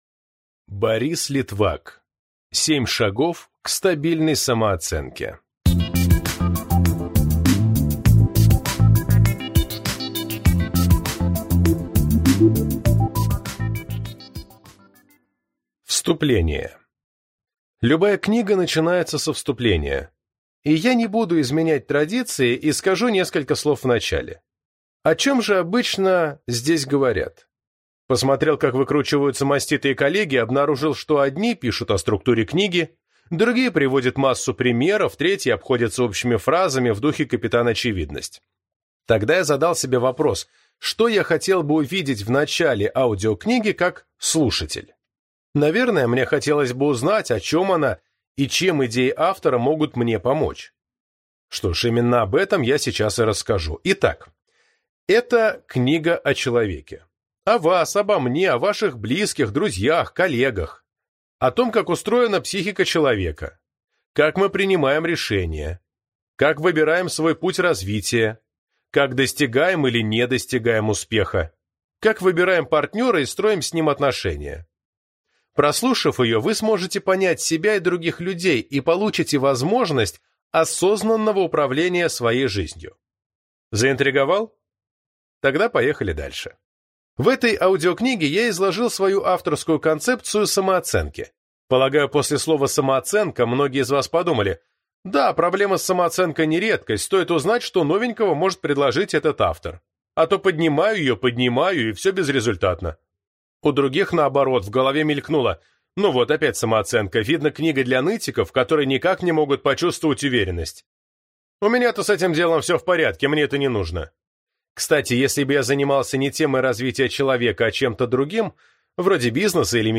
Аудиокнига 7 шагов к стабильной самооценке | Библиотека аудиокниг